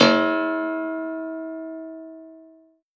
53w-pno02-E2.wav